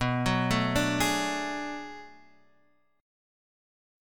Bm13 chord